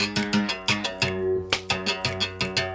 32 Berimbau 02.wav